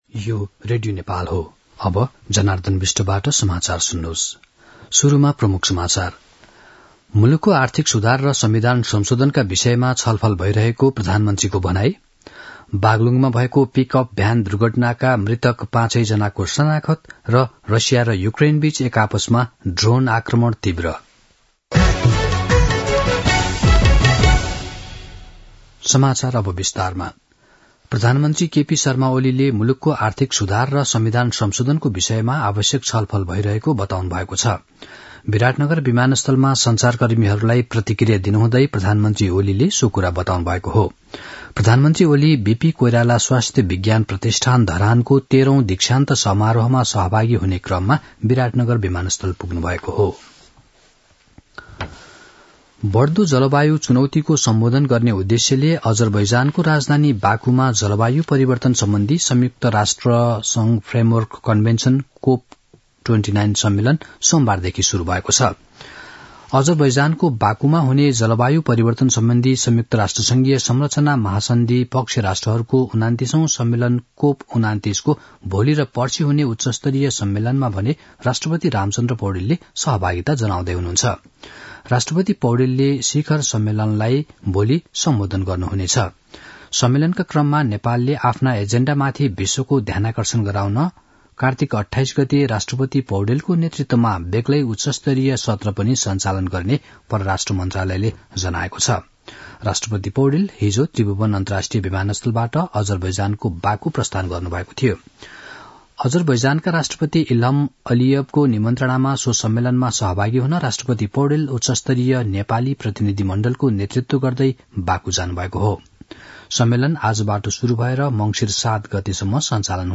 दिउँसो ३ बजेको नेपाली समाचार : २७ कार्तिक , २०८१
3-pm-news-.mp3